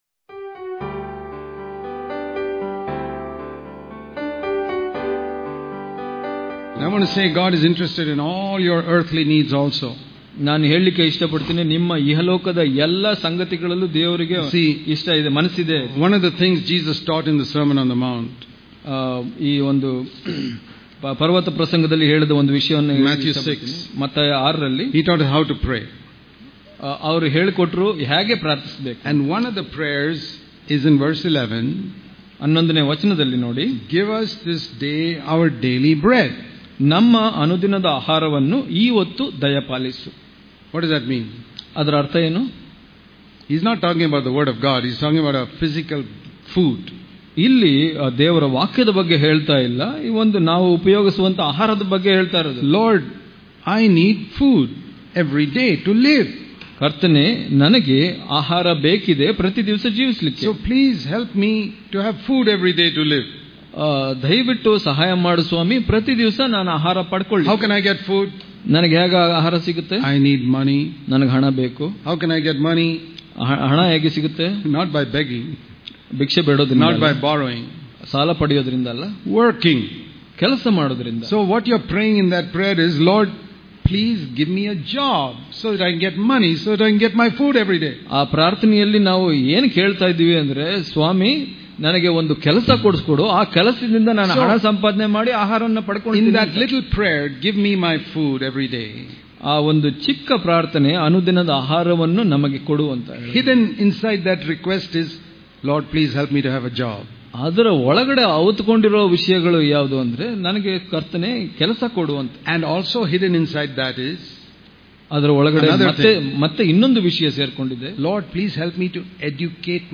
Daily Devotions